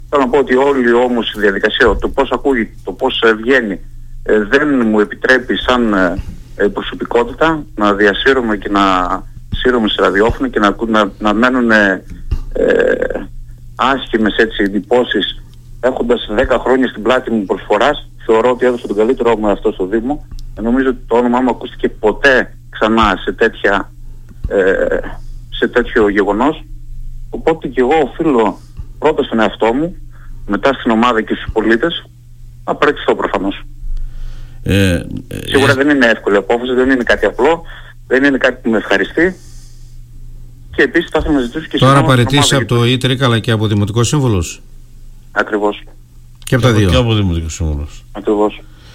«Η παραίτηση μου είναι από χθες βράδυ στα χέρια του δημάρχου» δήλωσε στο Ραδιο Ζυγός ο Φόρης Ρόμπος που εξήγησε πως έγινε η ανάθεση των 30.000 ευρώ.